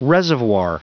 Prononciation du mot reservoir en anglais (fichier audio)
Prononciation du mot : reservoir